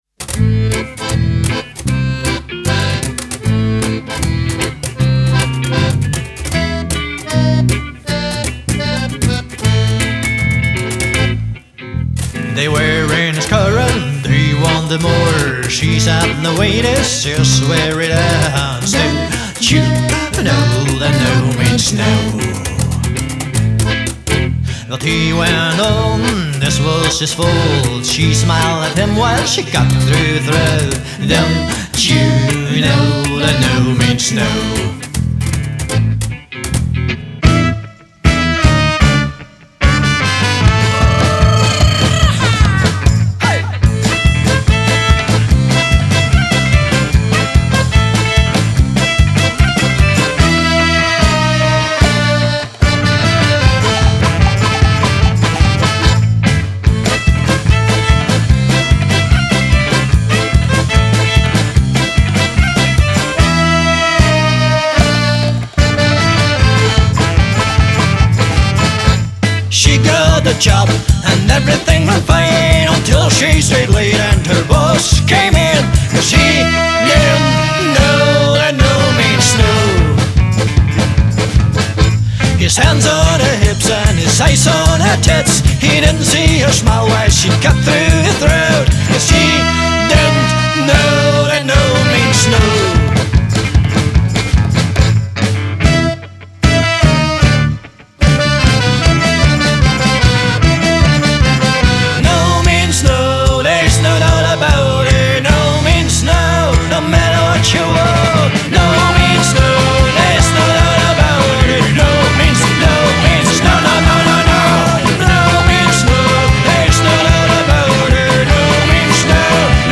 Genre: Folk-Rock, Gypsy-Punk